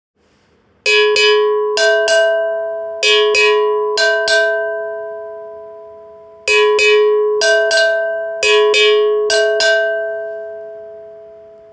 鉄ベル アゴゴ カウベル アフリカンベル （p010-19）
西アフリカのトーゴで作られたアフリカン鉄ベルです。高低2種の音が出ます。
真鍮のような響きはありませんが野性味溢れたアフリカらしい素朴な音を出します。
この楽器のサンプル音